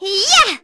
Artemia-Vox_01.wav